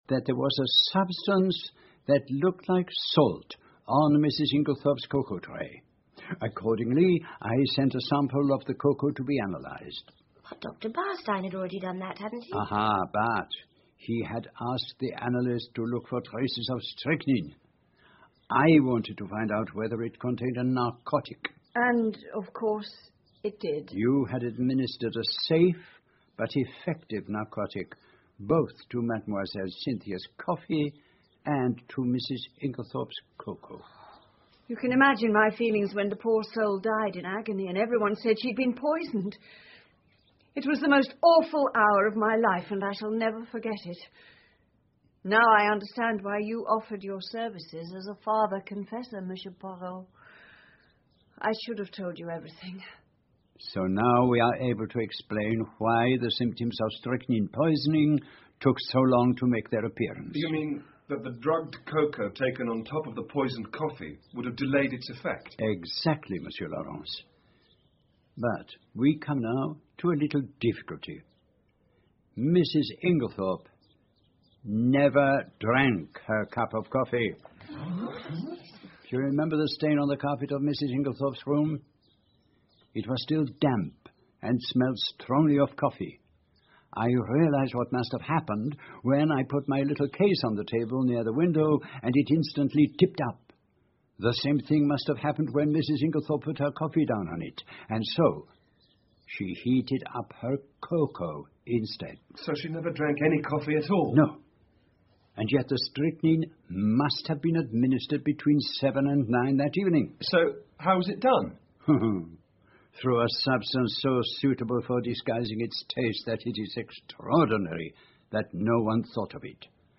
英文广播剧在线听 Agatha Christie - Mysterious Affair at Styles 23 听力文件下载—在线英语听力室